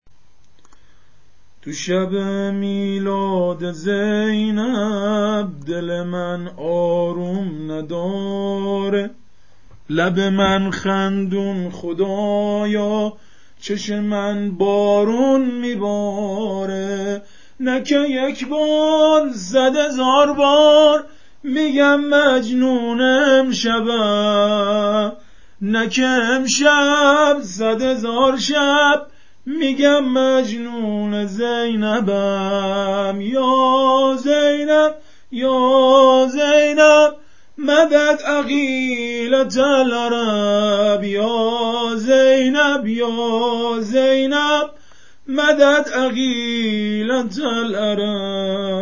سبک مولودی حضرت زینب